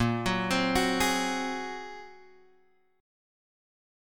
A# 7th Suspended 2nd Sharp 5th